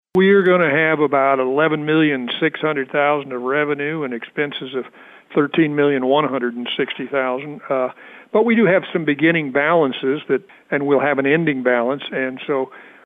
Presiding Commissioner Ed Douglas broke down some of the primary numbers.